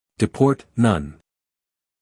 英音/ dɪˈpɔːt / 美音/ dɪˈpɔːrt /